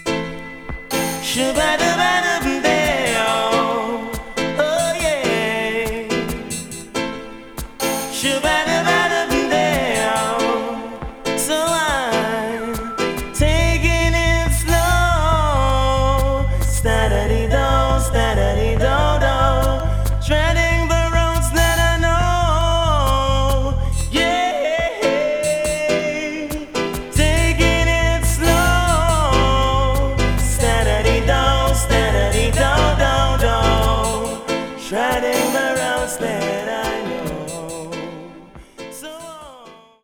Genre: Dancehall, Reggae